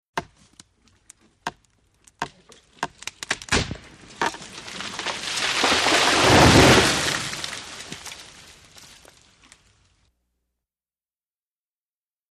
TreeFallStresCreak PE698801
IMPACTS & CRASHES - FOLIAGE TREE: EXT: Large tree falling, stress creaks, trunk snap, fall to ground, foliage movement.